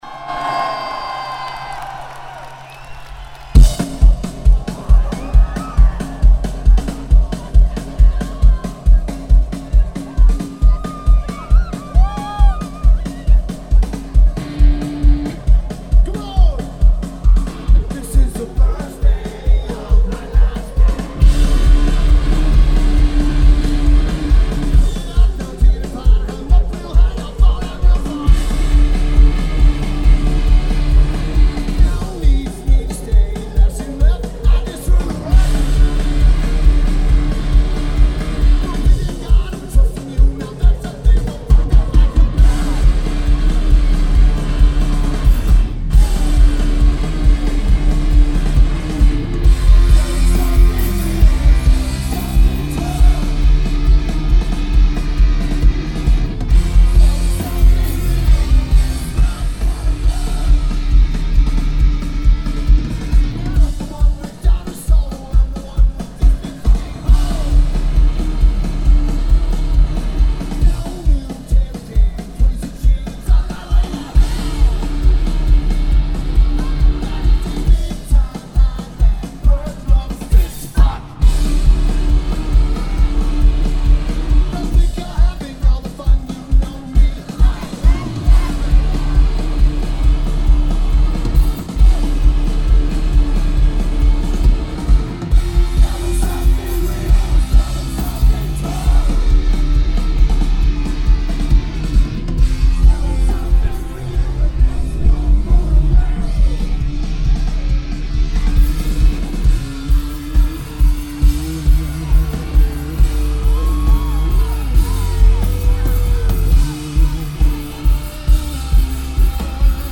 Lineage: Audio - AUD (Zoom Q3HD)